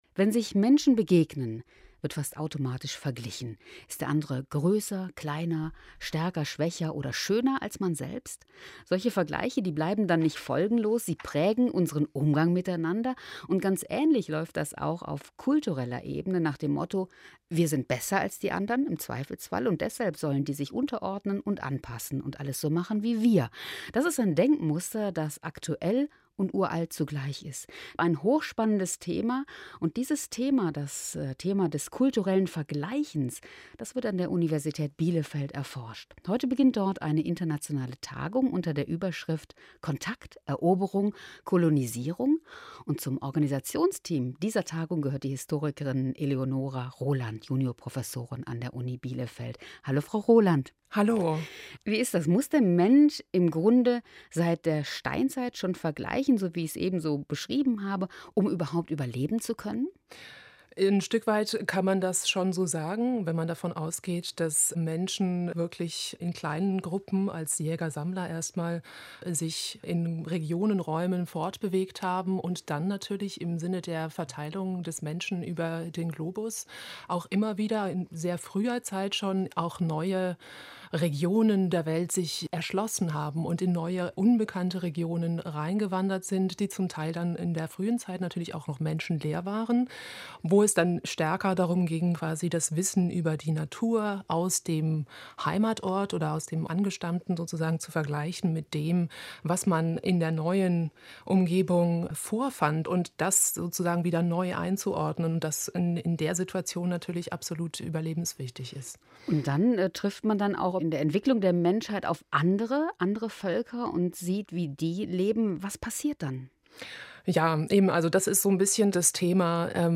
für ein Radiointerview mit WDR 3 und der Sendung "Kultur am Mittag"